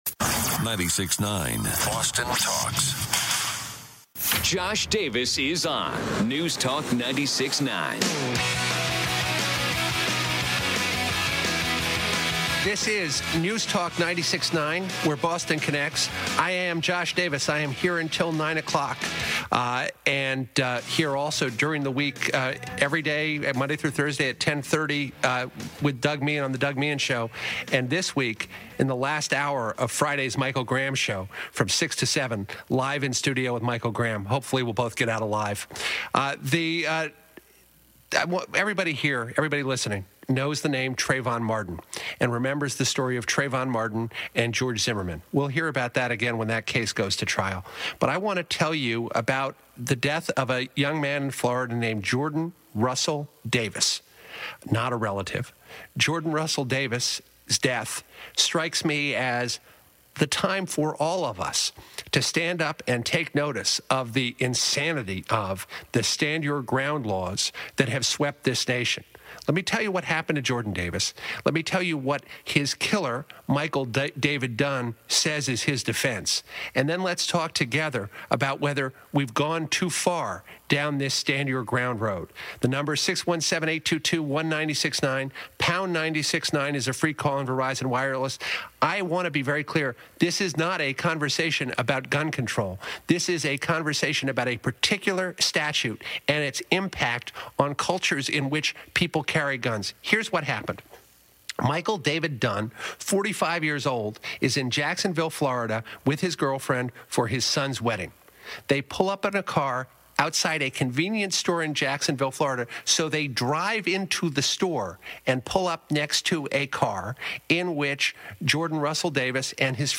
Radio segments
Here is selected audio from my radio appearances —